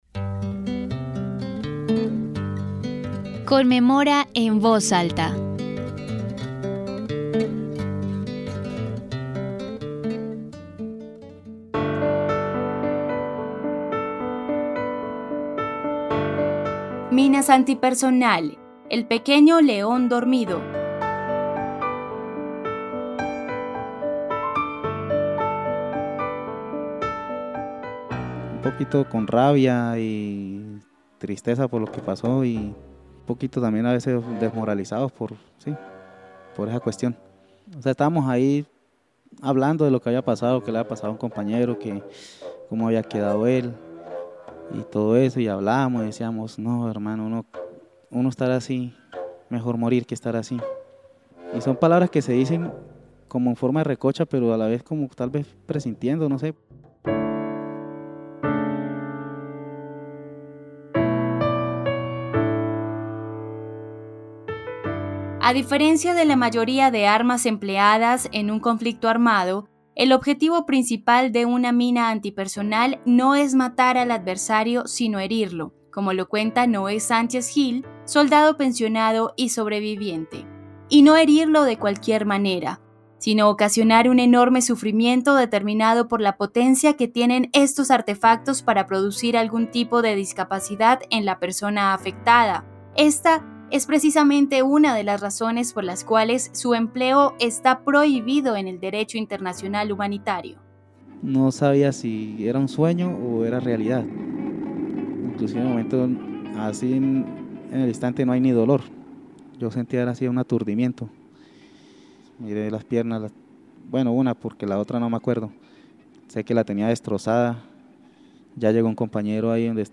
Descripción (dcterms:description) Capítulo número 6 de la tercera temporada de la serie radial "Conmemora en voz alta".